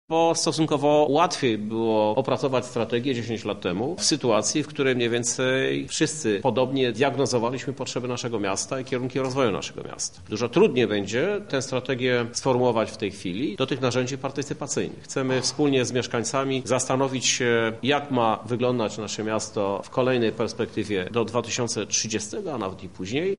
Prezydent Krzysztof Żuk mówi, że realizacja zadania będzie stanowić spore wyzwanie: